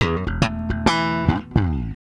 Bass Lick 35-05.wav